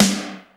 HR16B SNR 07.wav